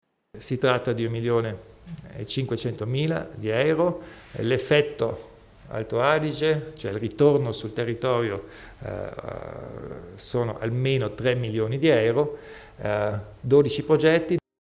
Il Presidente Kompatscher elenca i dati dei progetti di sostegno alla produzione cinematografica